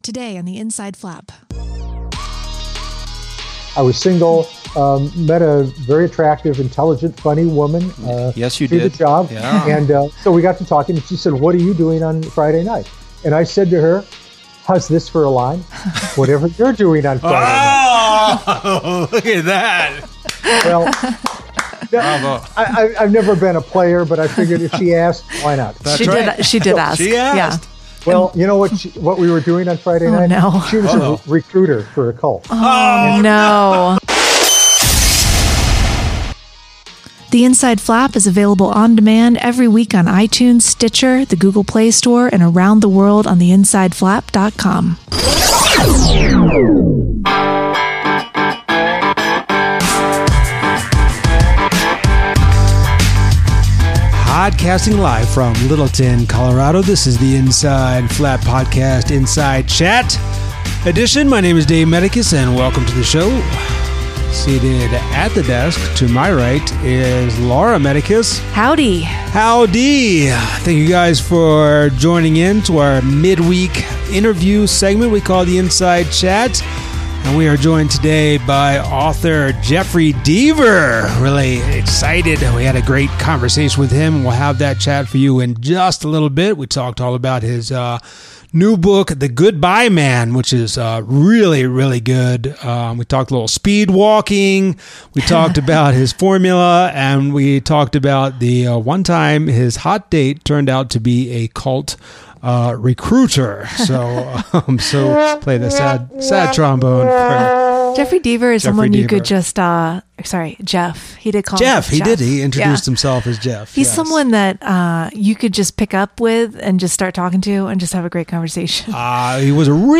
An “Inside Chat” edition with author Jeffery Deaver! We chat about speed walking, his excellent new book The Goodbye Man, the Deaver formula, and that time his hot date turned out to be a cult recruiter.